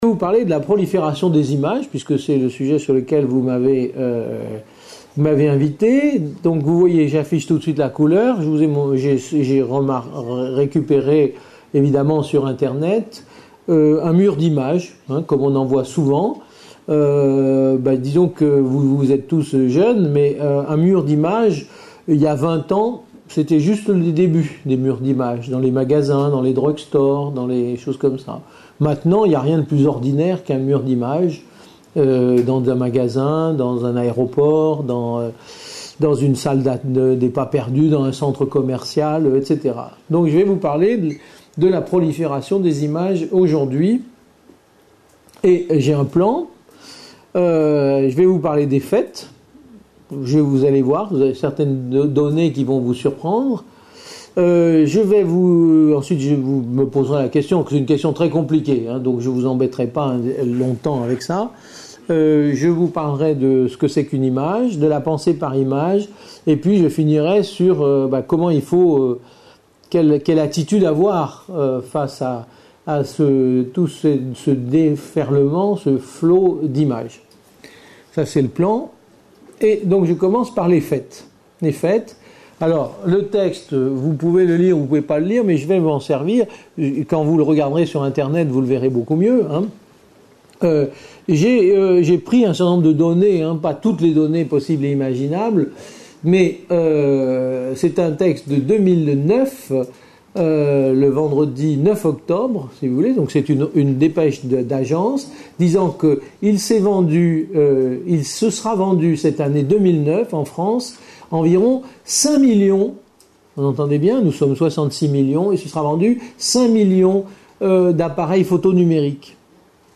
Une conférence de l'UTLS au Lycée.